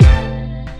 emn kick 4.wav